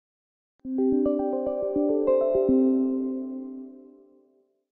Звуки приветствия Windows
Звук запуска Windows 10